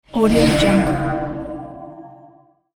دانلود افکت صدای تونل بادی
• صدای باد ملایم و آرام
• صدای باد قوی و قدرتمند
• صدای باد طوفانی و گردبادی
16-Bit Stereo, 44.1 kHz